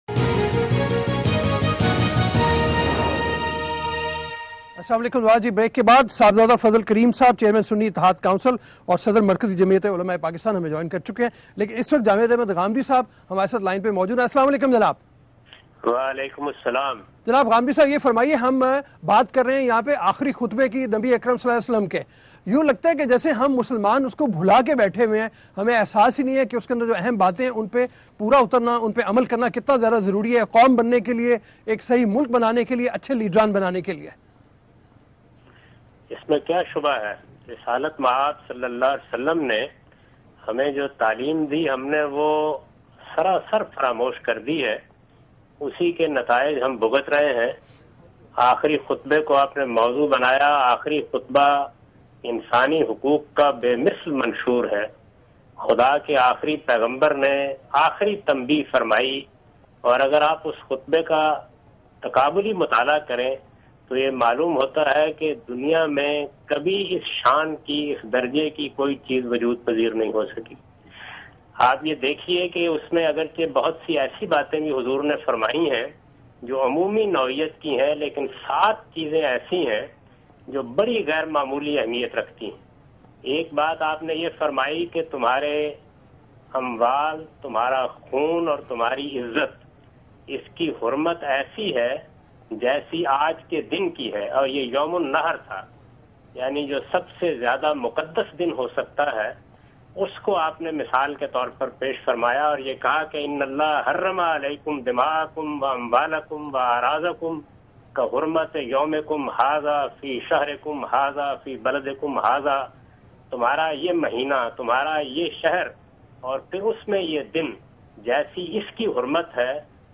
Category: TV Programs / Geo Tv / Questions_Answers /